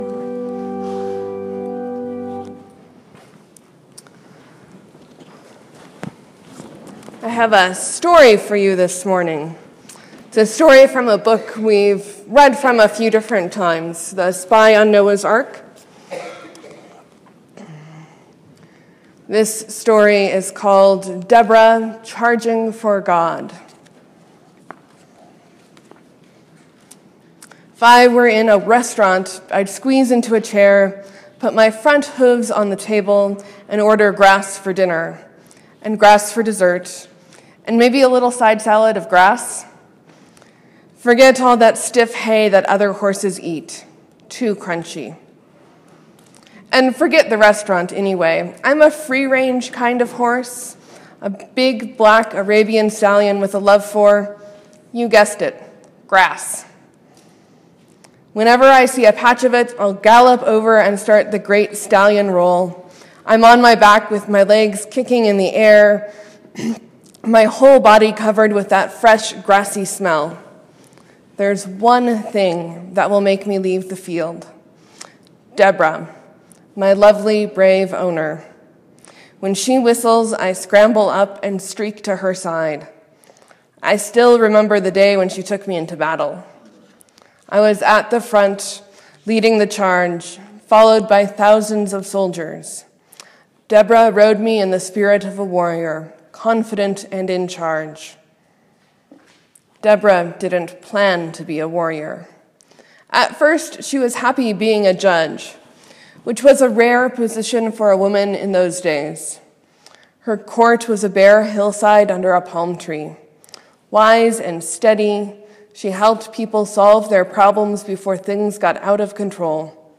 Morsels & Stories: I read from The Spy on Noah’s Ark.
Sermon: It’s the complex parallel of the Unjust Judge or the Persistent Widow. We consider some of the levels of meaning in the story and ask about God’s justice.